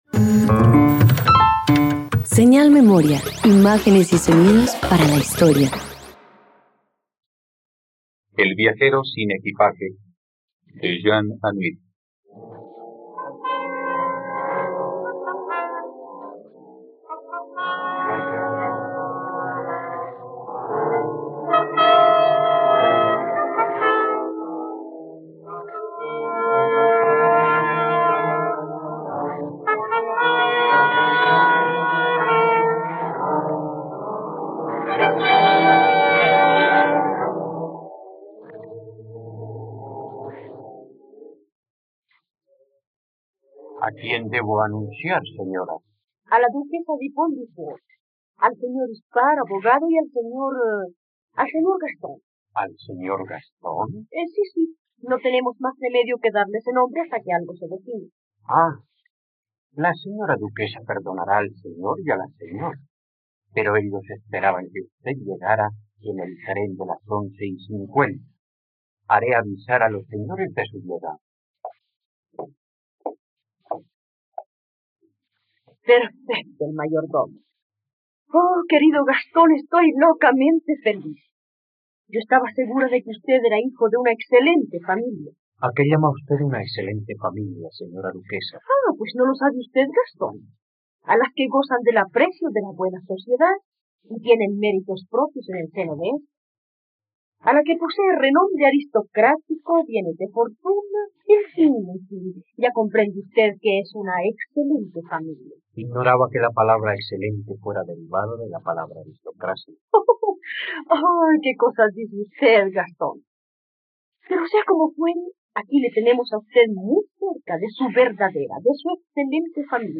..Radioteatro. Escucha la adaptación de la obra “El viajero sin equipaje" del dramaturgo francés Jean Anouilh en la plataforma de streaming RTVCPlay.